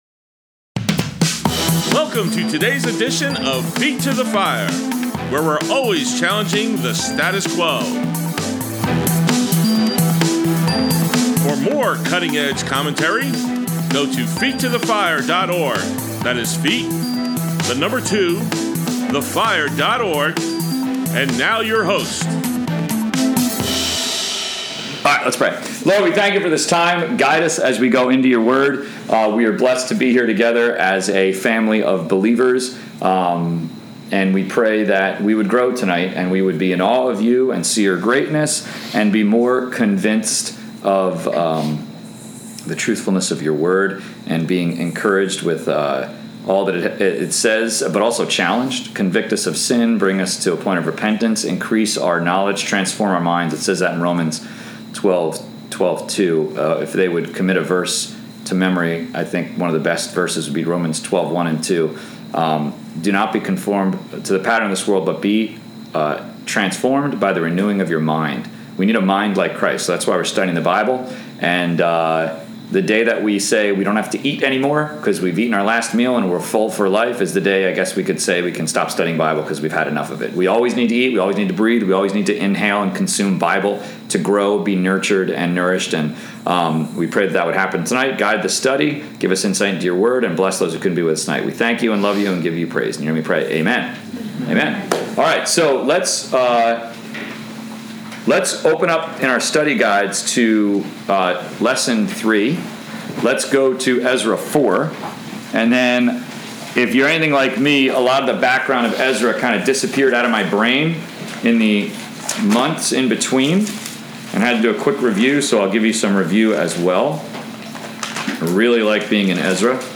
Saturday Sermons, 9/19/20